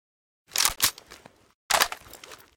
aug_misfire.ogg